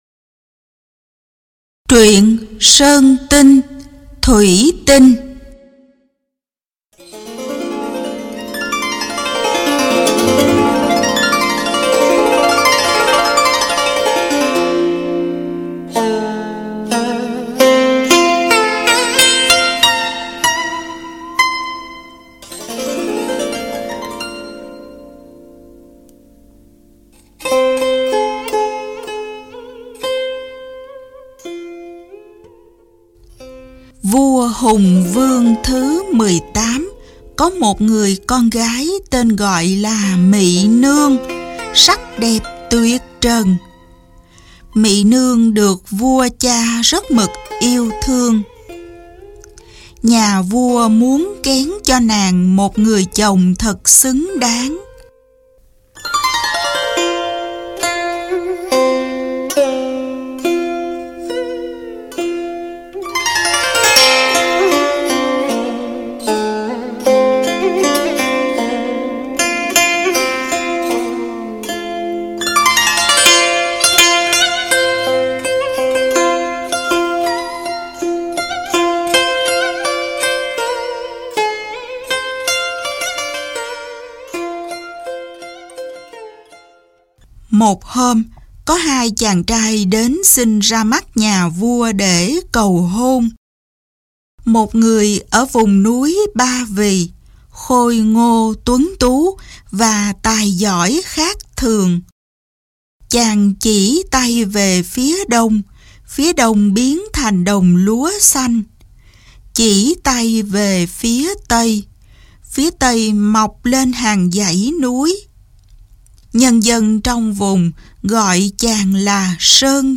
Sách nói | Sơn Tinh Thủy Tinh